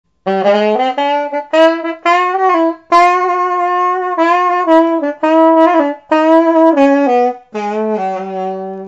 Saxo de bambú en Do3 Tienen una digitación propia, que facilita la obtención de semitonos.
A pedido se realizan en Sib Sonido de saxo de bambú en Do 3 con pabellón de asta ver video